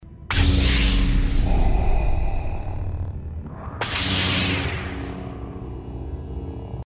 Звуки Дарт Вейдера